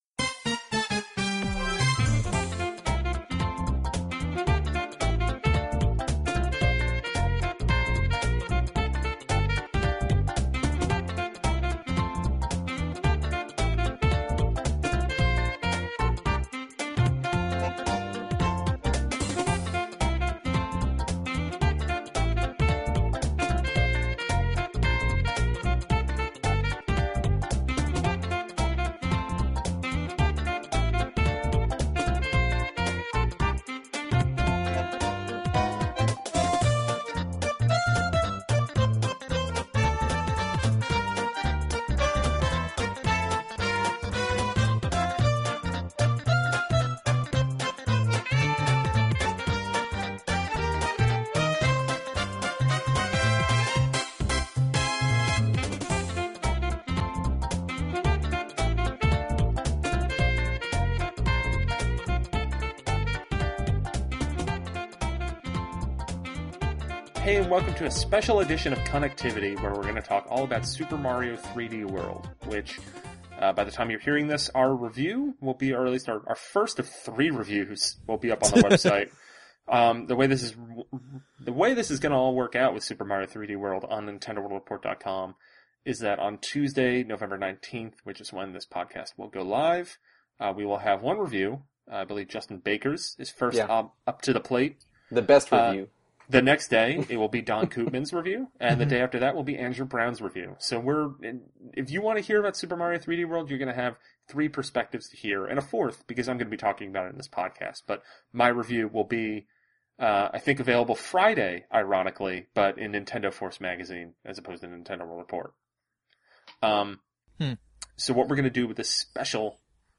But fear not, unlike in the Nintendo Direct, the boys give a clear spoiler warning halfway through when they begin to cover specific levels and bosses.